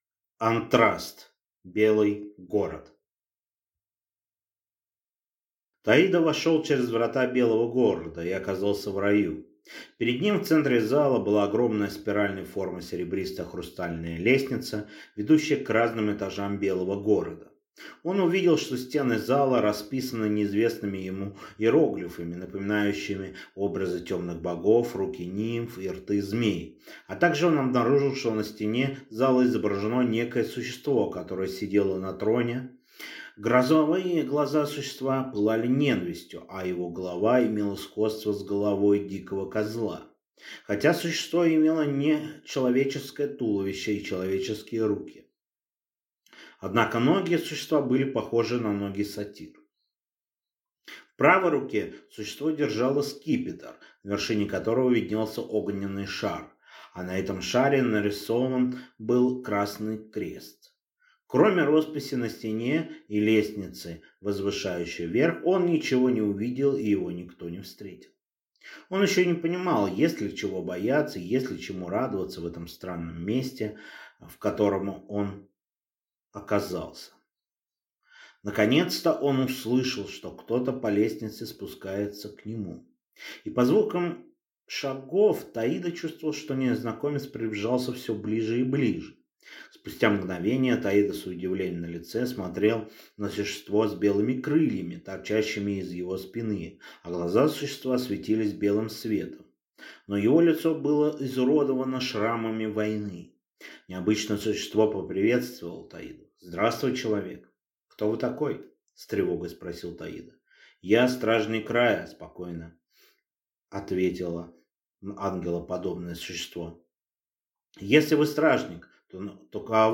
Аудиокнига Антраст. Белый город | Библиотека аудиокниг